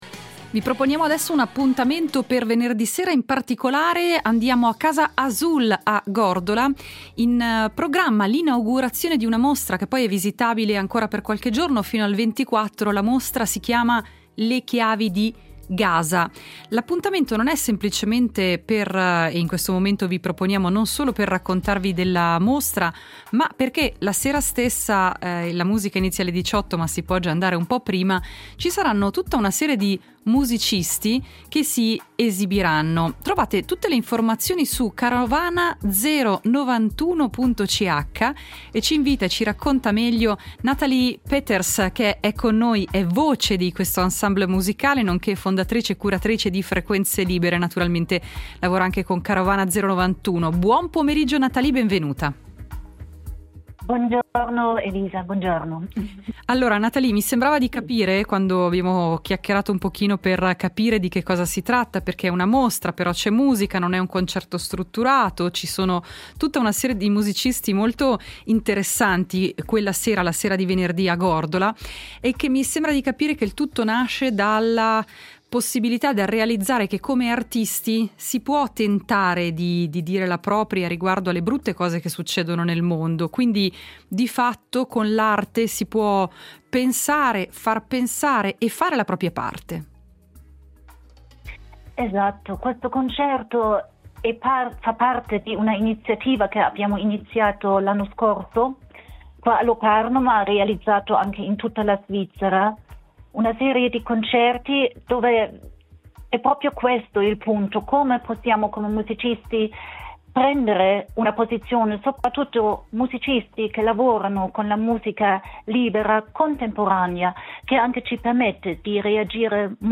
Alarm and Silence: Interview